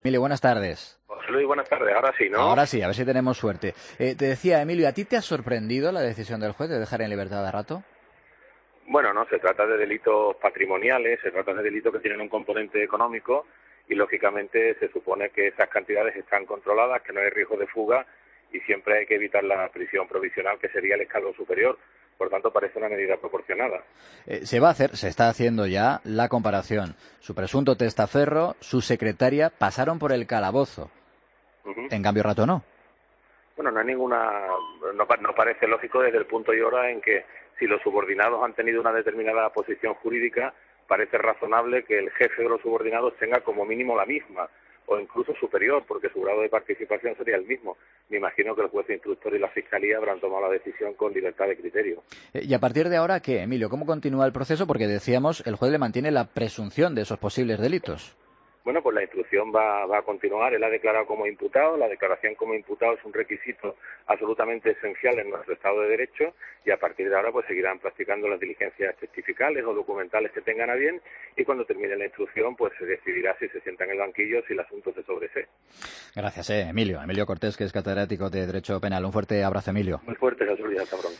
AUDIO: Escucha al catedrático de derecho Penal en Mediodía COPE